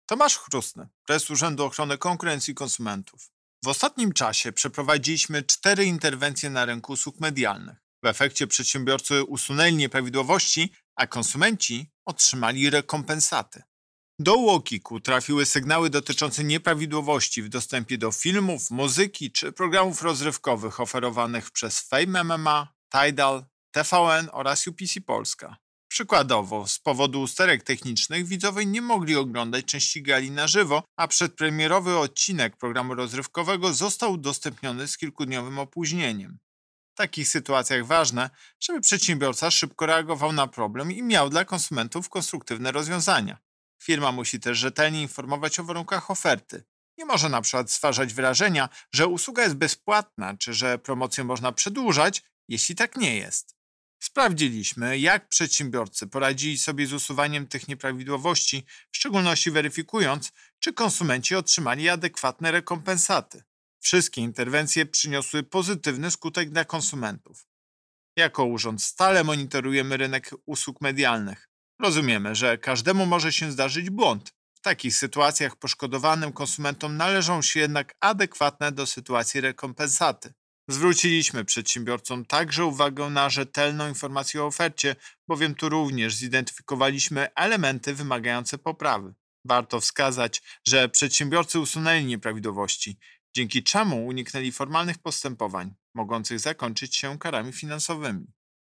Wypowiedź Prezesa UOKiK Tomasza Chróstnego z 2 sierpnia 2021 r..mp3